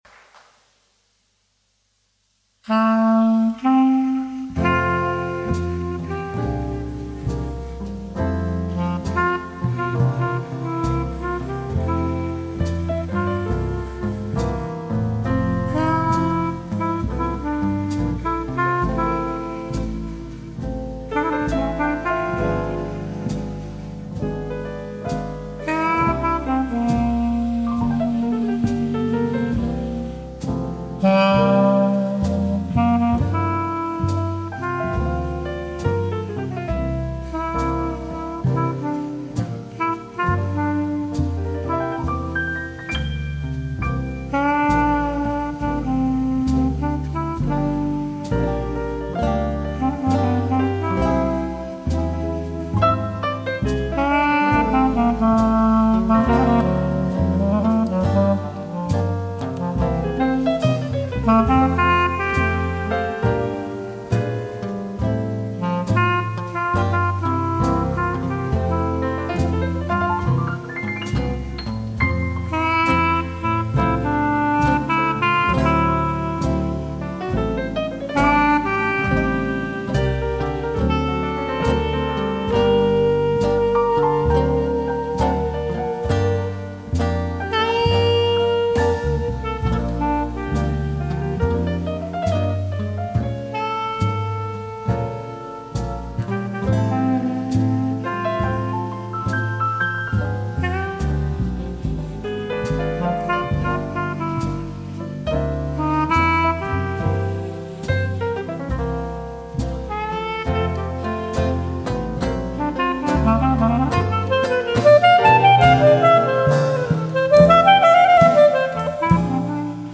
А так как мне почти всегда нравится своеобразное триединство ( клипповость)) - изображение (в данном случае -фото) + соответствующая музыка +  слова (найти самые подходящие слова - чуть ли не самое сложное)),  то и появилась ещё  и музыка - между спокойной оркестровой и джазом - предпочтение отдано джазу - а комментарии показались  ненужными.))